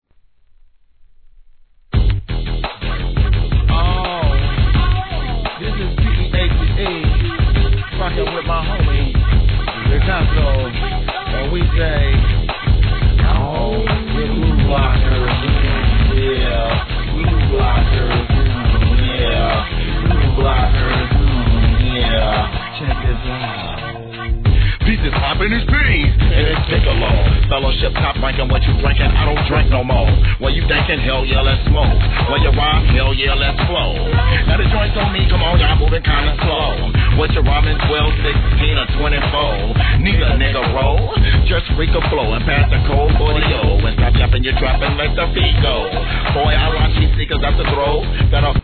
G-RAP/WEST COAST/SOUTH
イントロから激シブなベースラインが印象的なトラックで、COOLなマイク・リレーが見事なオフェンス!!